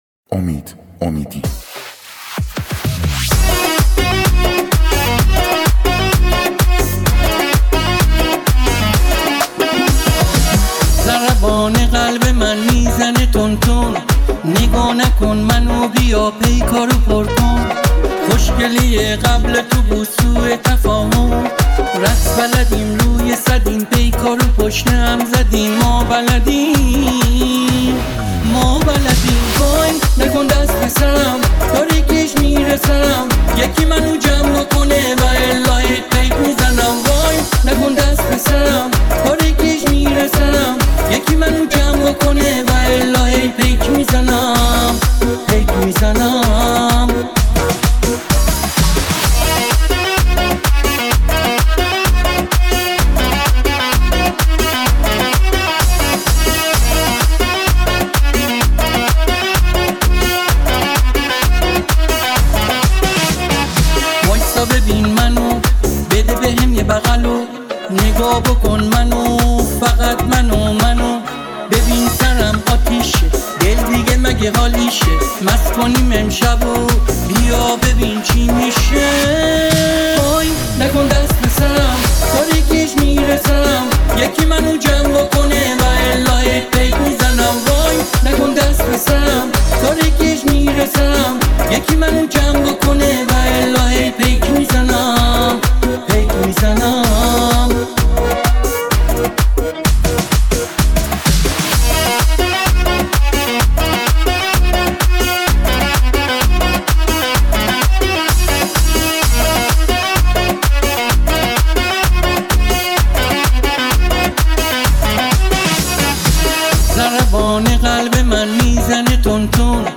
تک آهنگ
پاپ
آهنگ با صدای زن